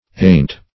ayont - definition of ayont - synonyms, pronunciation, spelling from Free Dictionary Search Result for " ayont" : The Collaborative International Dictionary of English v.0.48: Ayont \A*yont"\, prep. & adv.